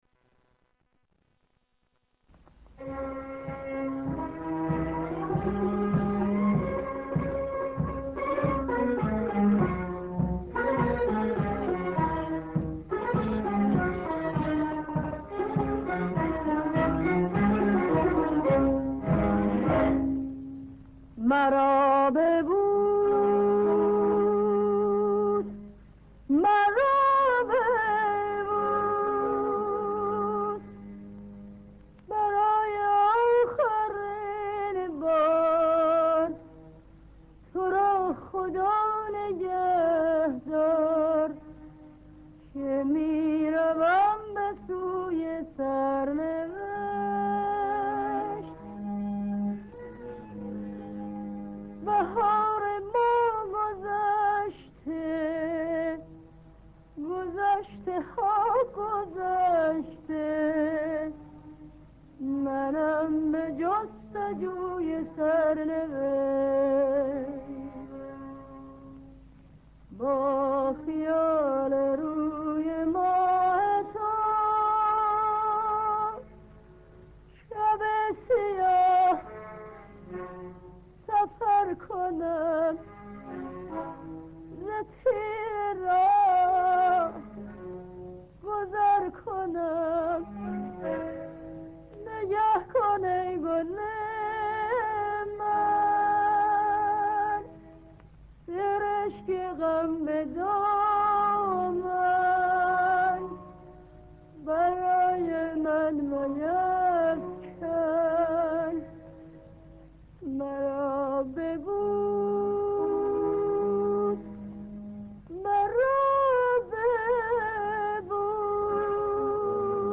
ترانه عاشقانه غم‌انگیزی